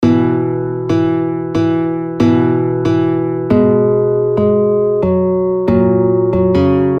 永不消失的原声吉他1
描述：原声吉他......听起来很甜:)原声乡村嘻哈等
Tag: 69 bpm Acoustic Loops Guitar Electric Loops 1.17 MB wav Key : Unknown